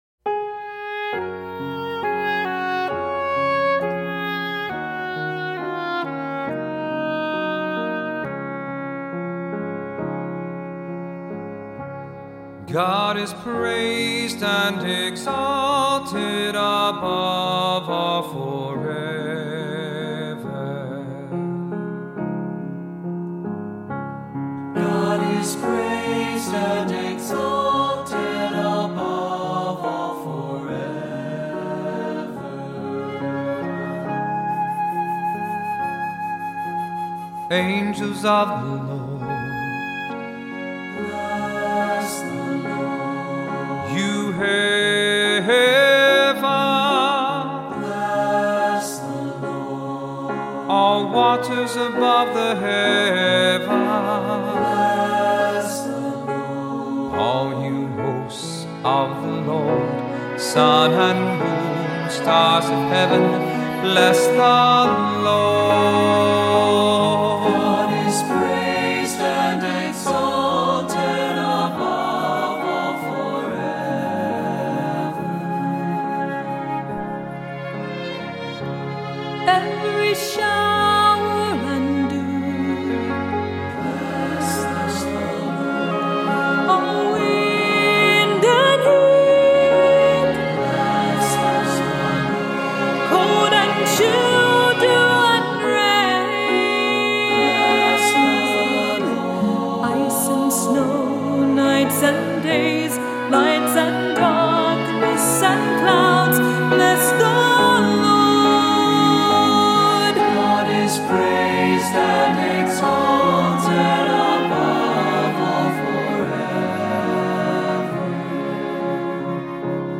Voicing: "SATB","Soprano Descant","Cantor","Assembly"